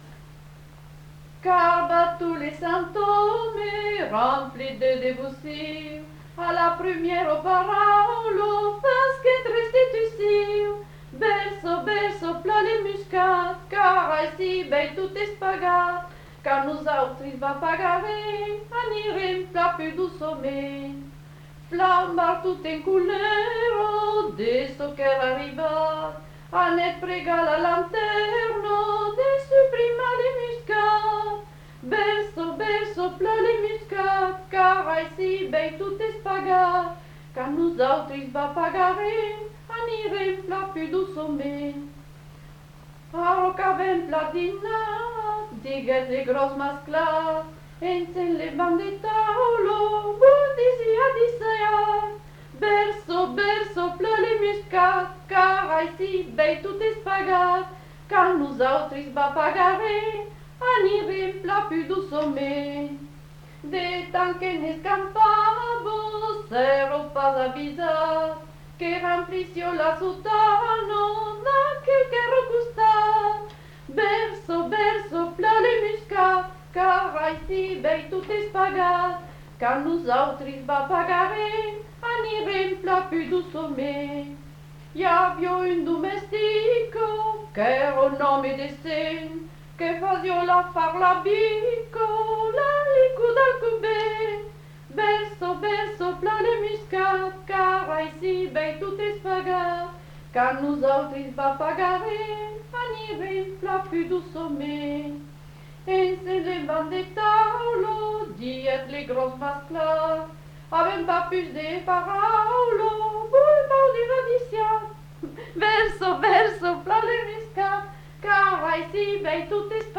Aire culturelle : Cabardès
Lieu : Mas-Cabardès
Genre : chant
Effectif : 1
Type de voix : voix de femme
Production du son : chanté
Ecouter-voir : archives sonores en ligne